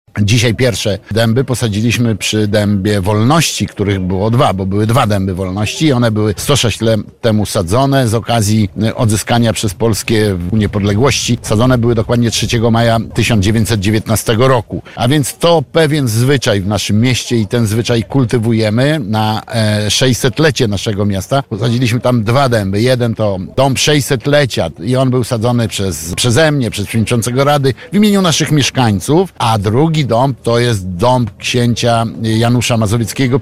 Burmistrz Kolna Andrzej Duda mówił na naszej antenie, że sadzenie drzew w związku z ważnymi rocznicami jest już w mieście tradycją.